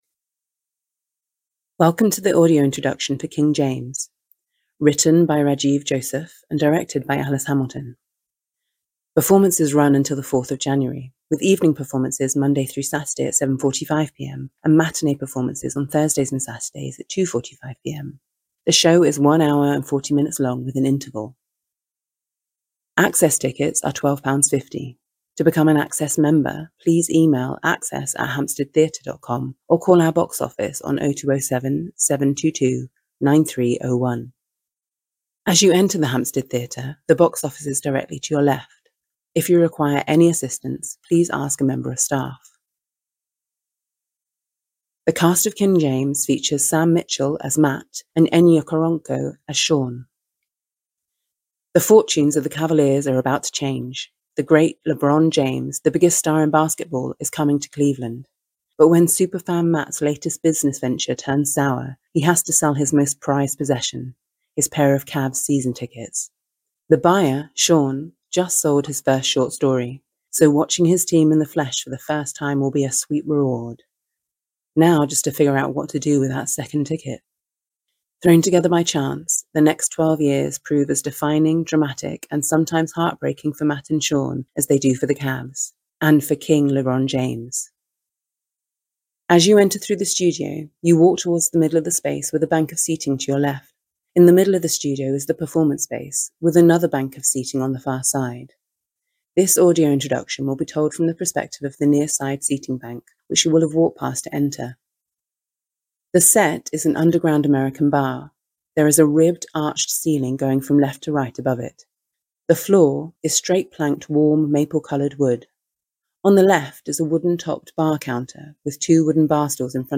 An Audio Introduction is available for this production. This is a pre-recorded introduction describing the set, characters and costumes and includes an interview with the cast.